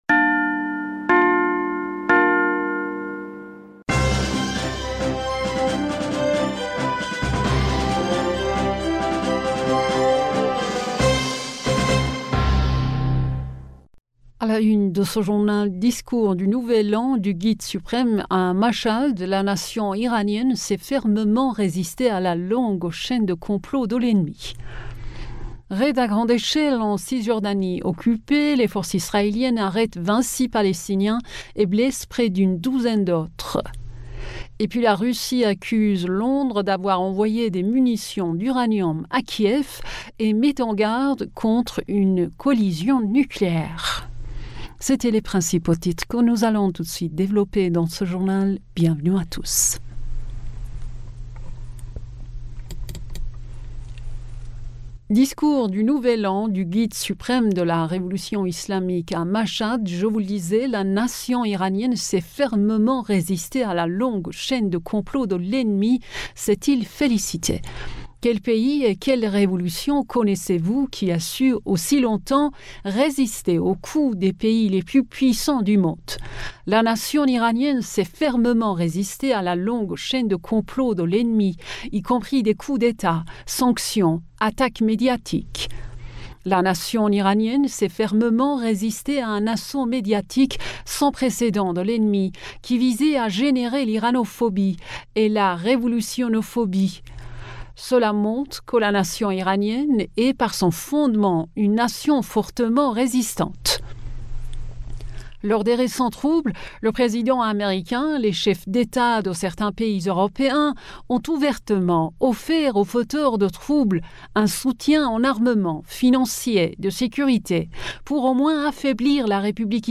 Bulletin d'information du 22 Mars